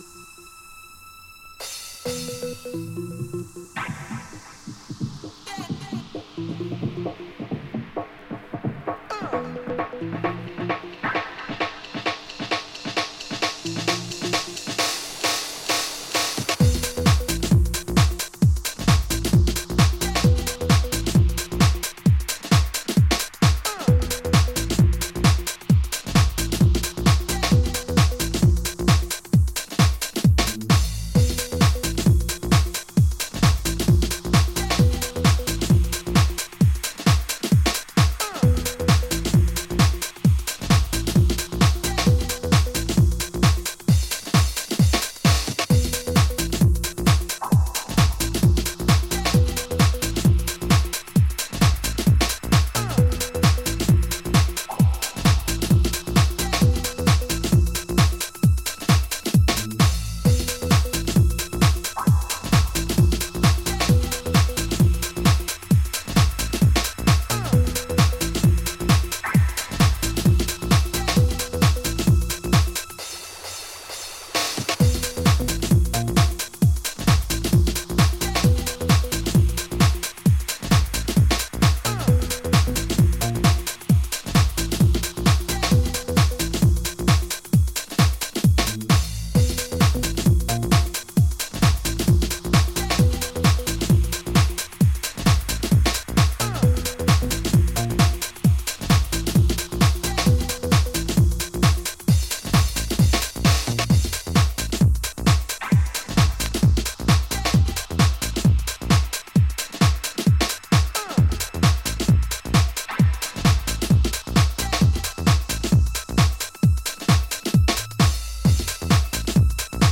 跳ねたグルーヴとメロディックなリフが噛み合う